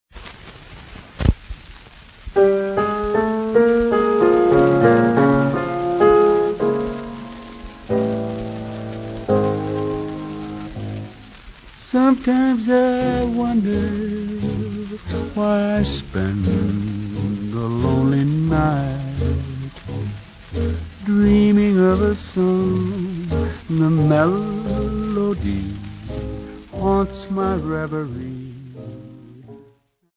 Popular music--Africa
Dance music
Vestax BDT-2500 belt drive turntable
96000Hz 24Bit Stereo